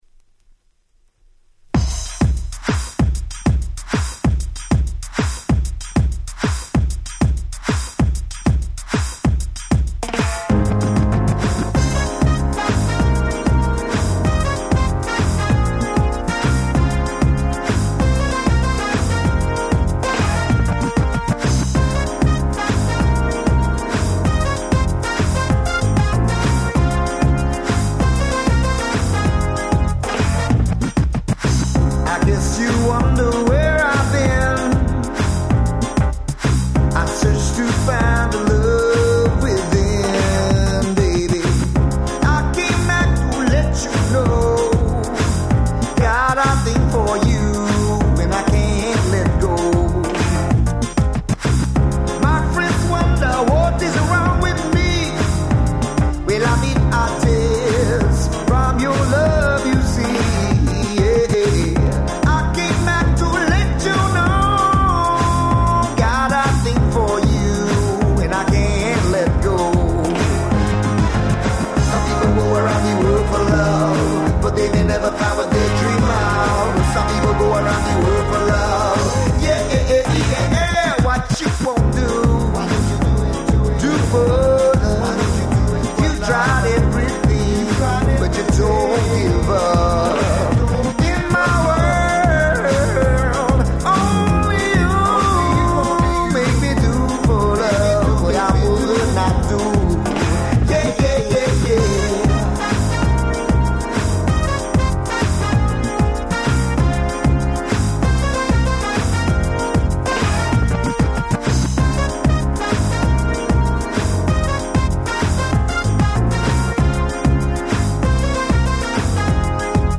国内企画盤Nice R&B♪
激キャッチー！！
ラガポップ Ragga Pop Reggae レゲエ